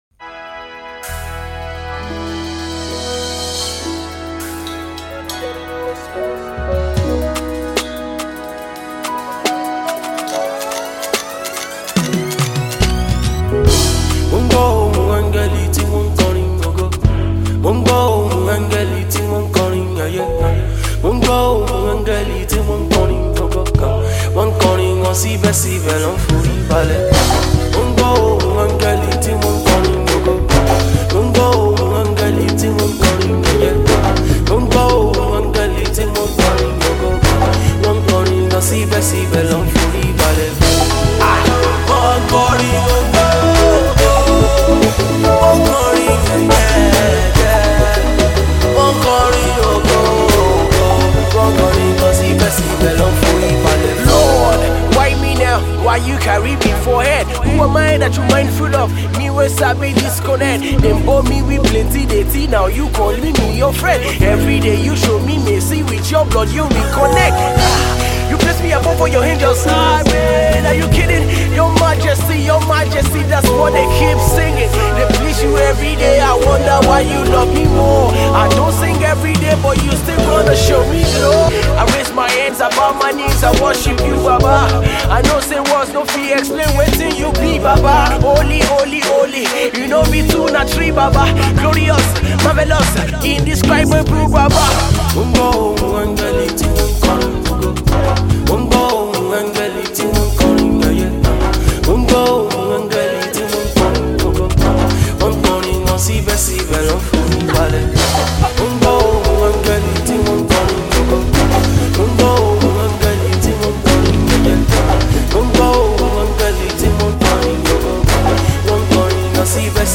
recorded live